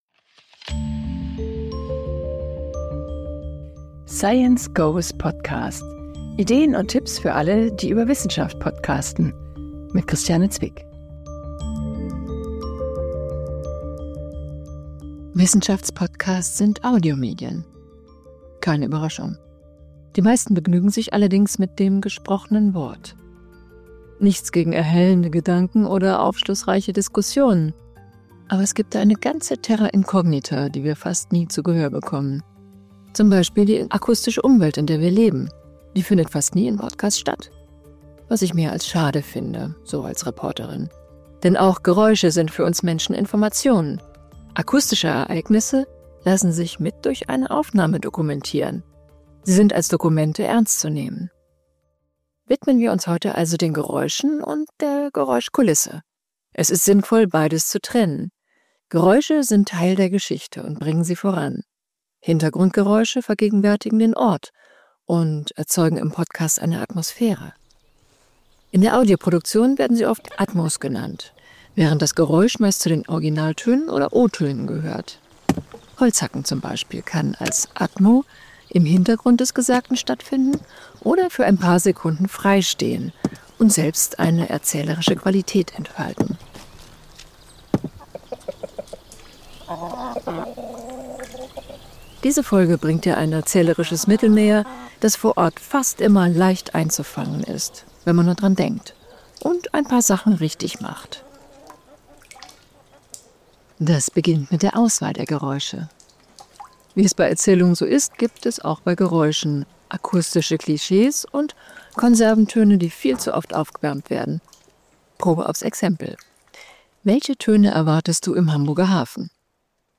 Gestaltungsmittel in dieser Episode: Geräusche Atmos Soundscapes Kommentar